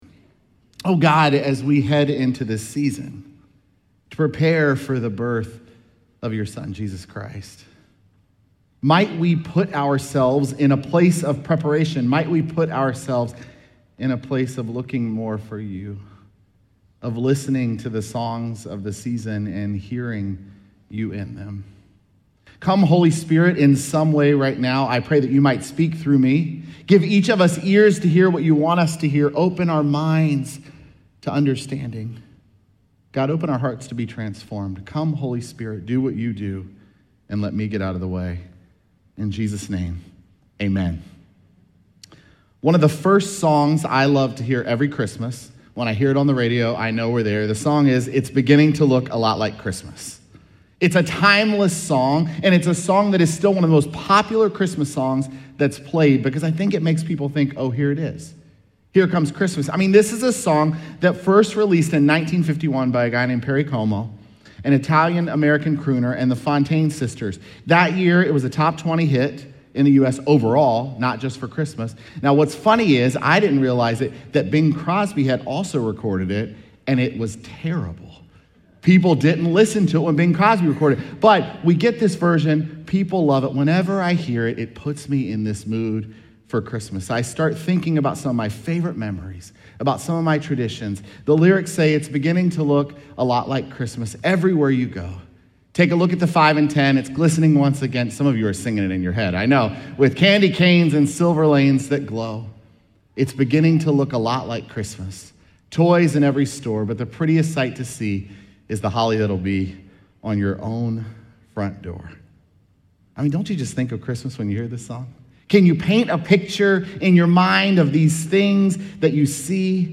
Sermons
Dec1SermonPodcast.mp3